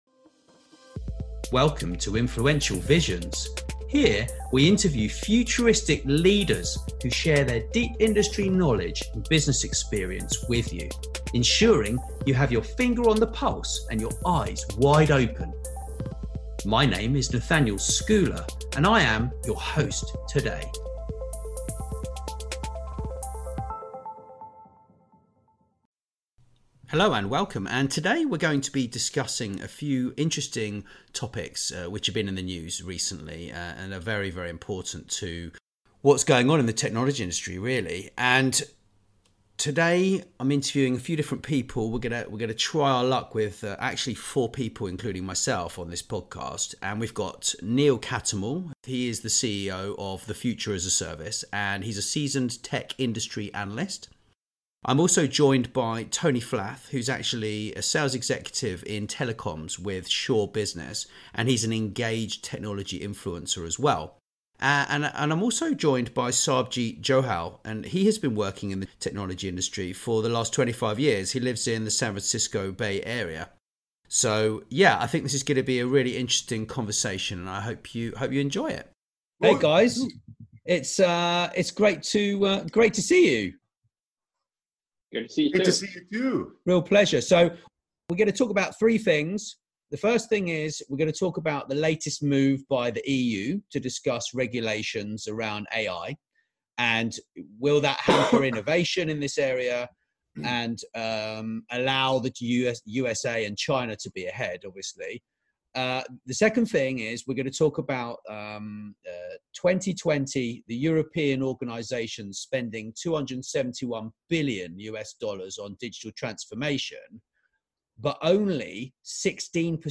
Regulating AI: Expert Panel Discussion
This weeks panel features a diverse knowledge base and experience in working with the technology industries top names.